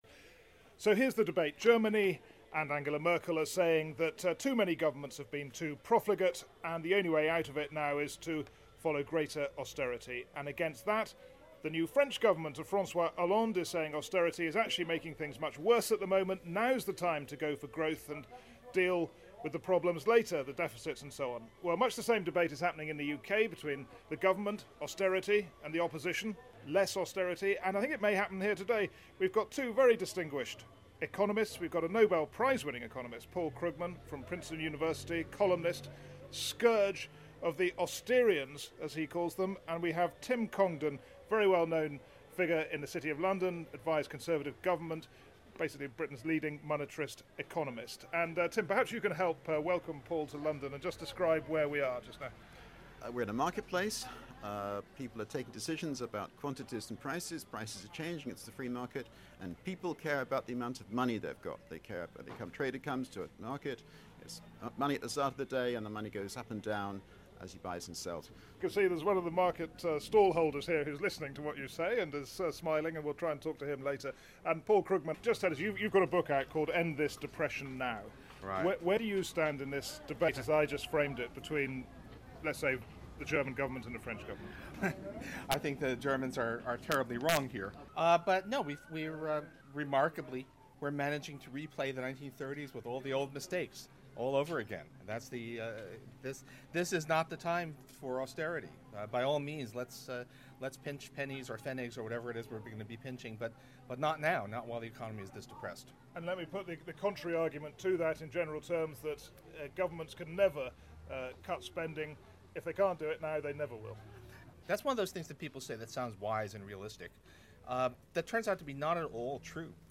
Owen Bennett Jones brings together Paul Krugman and Tim Congdon in a debate about economic growth and austerity.